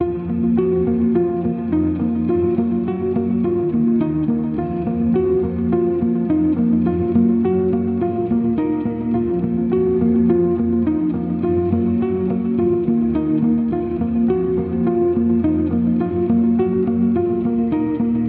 黑暗说唱环境吉他合唱团
在我看来，这首曲子的风格比较阴暗，但仍然可以成为一个很酷的说唱节拍。
Tag: 105 bpm Rap Loops Guitar Electric Loops 3.08 MB wav Key : A